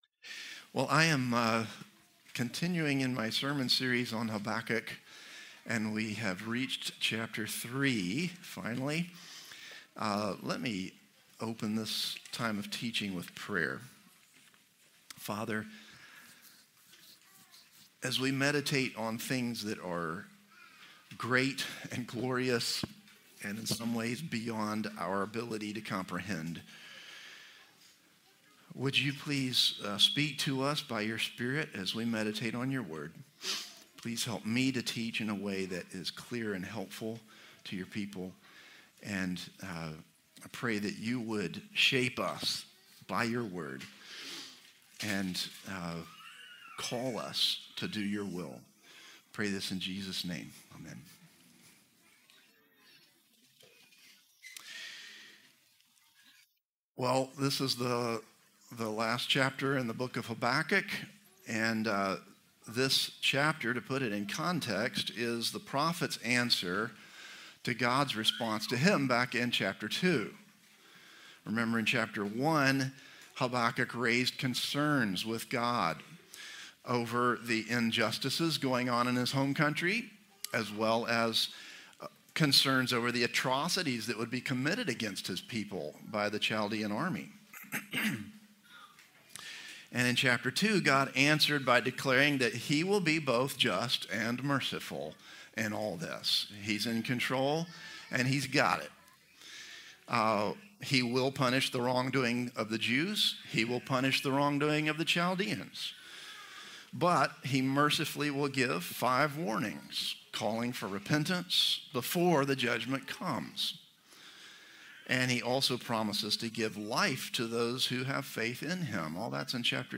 Christ the Redeemer Church | Sermon Categories God’s Character